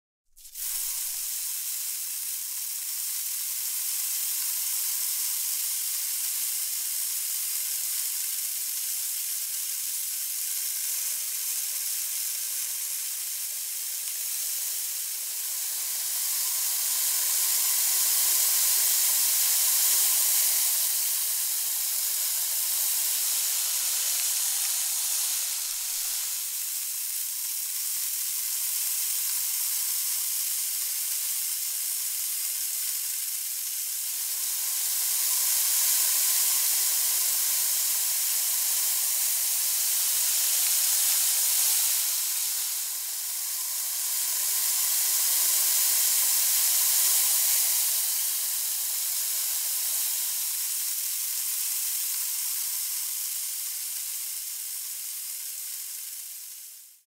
На этой странице собраны разнообразные звуки песка: от шуршания под ногами до шелеста дюн на ветру.
Звук медленно высыпаемого песка